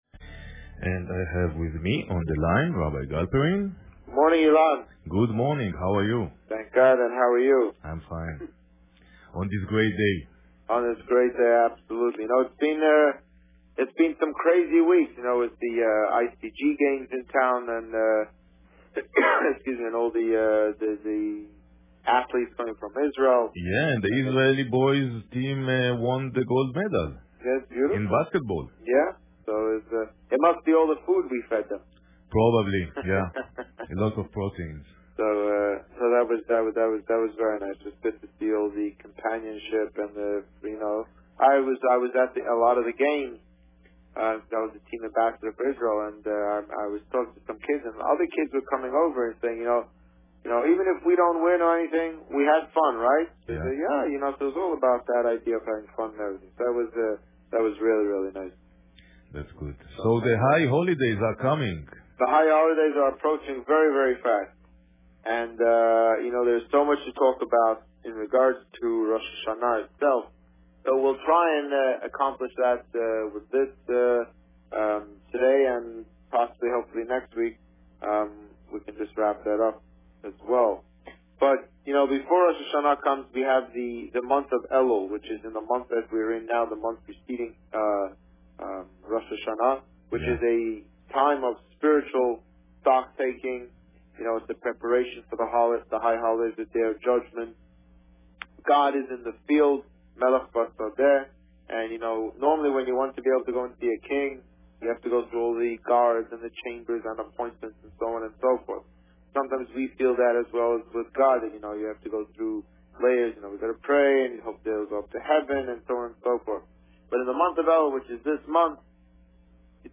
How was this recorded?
The Rabbi on Radio